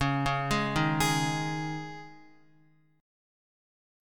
C#sus2 chord